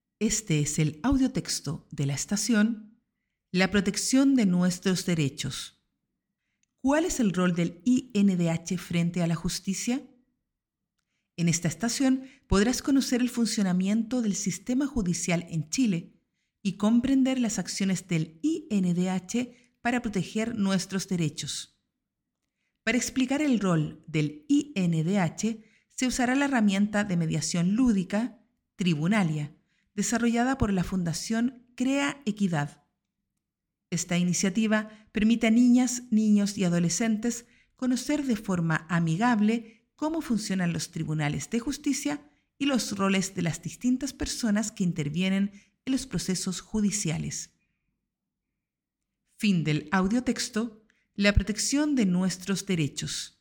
Audiotexto